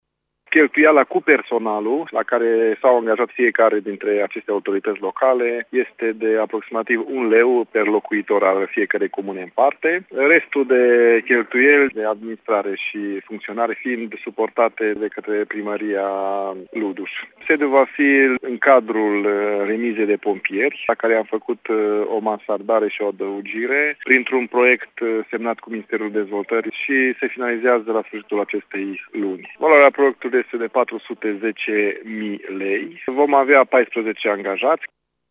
Asociația va deservi orașul Luduș și 8 comune limitrofe, însumând aproximativ 30.000 de oameni, a explicat primarul orașului, Cristian Moldovan: